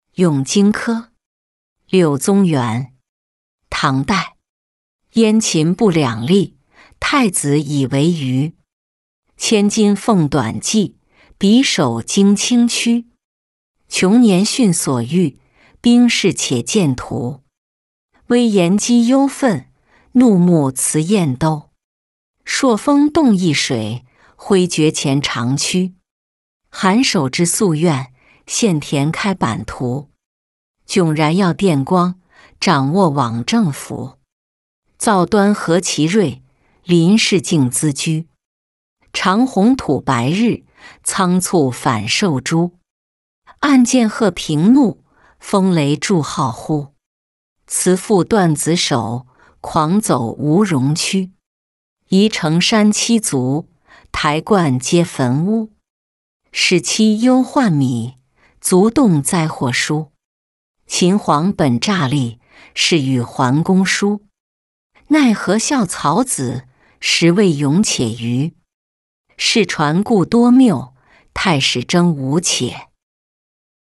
咏荆轲-音频朗读